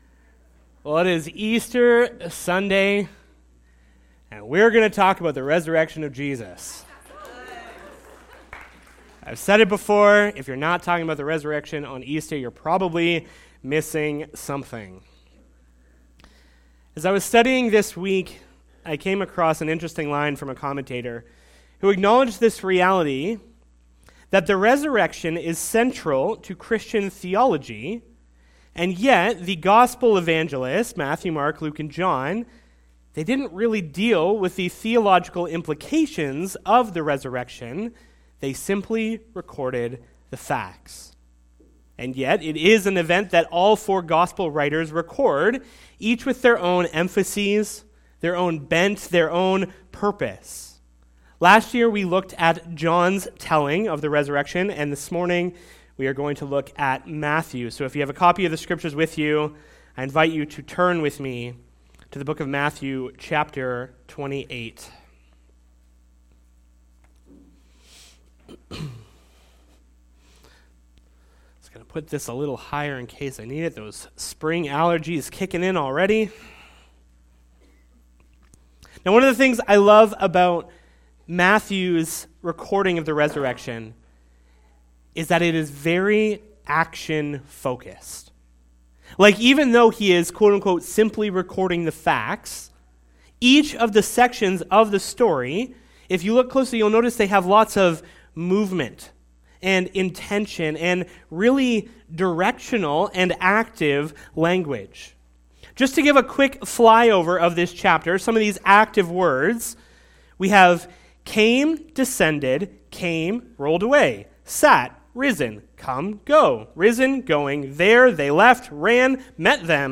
Sermon Audio and Video Go & Tell